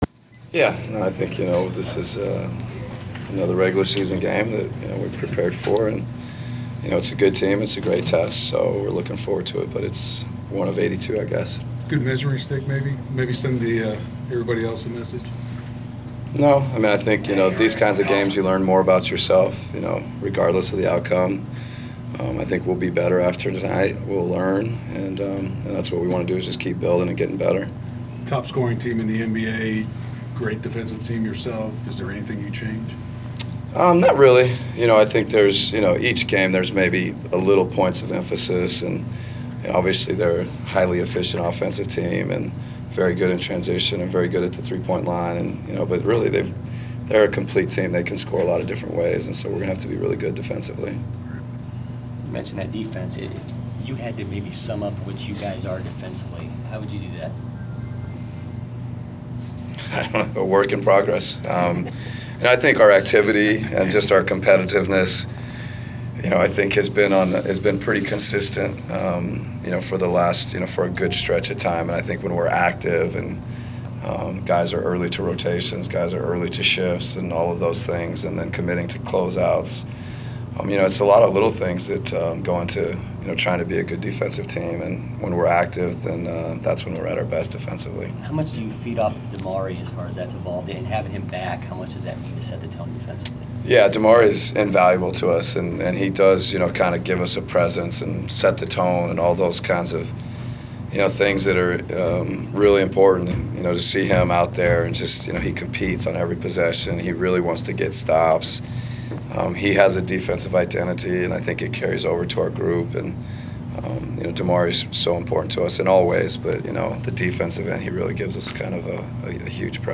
Inside the Inquirer: Pregame presser with Atlanta Hawks’ head coach Mike Budenholzer (2/6/15)
We attended the pregame presser of Atlanta Hawks’ head coach Mike Budenholzer before his team’s home game against the Golden State Warriors. Topics included facing Golden State and the overall defensive effort of Atlanta.